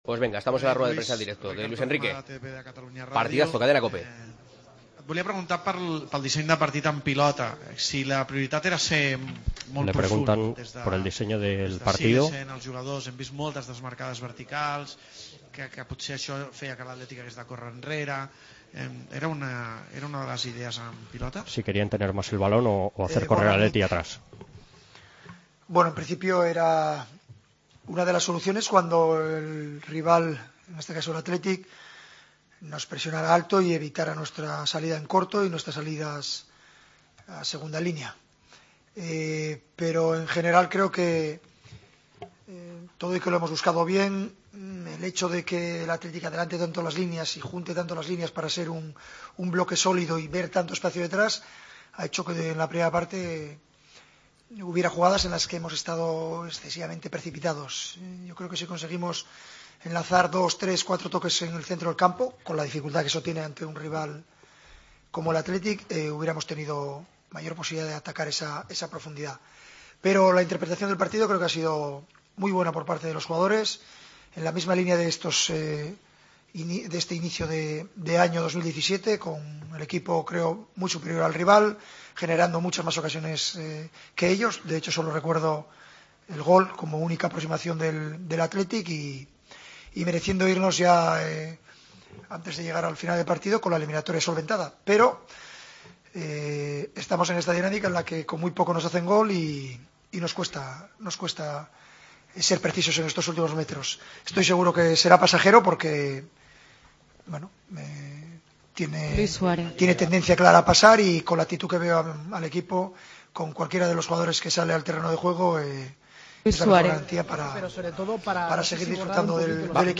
Escuchamos a Luis Enrique en la rueda de prensa posterior a la eliminatoria de Copa ante el Athletic: "Estamos en la dinámica de que con muy poco nos hacen gol, pero será algo pasajero.